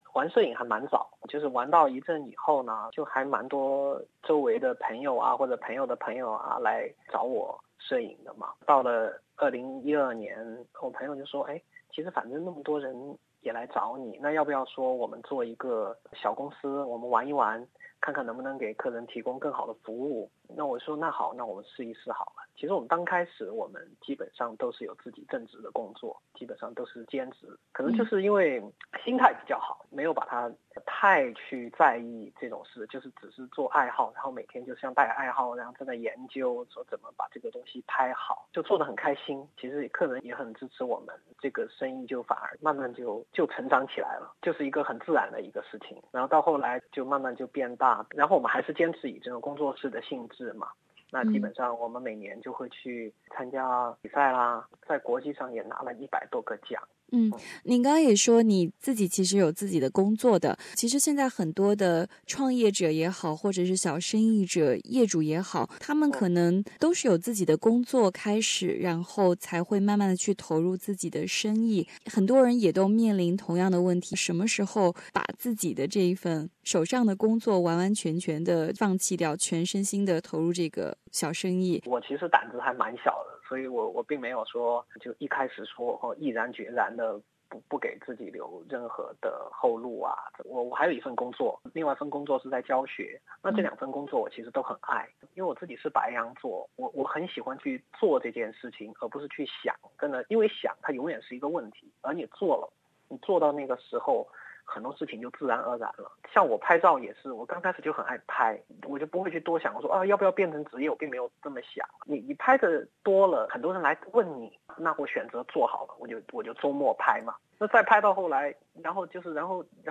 华商访谈系列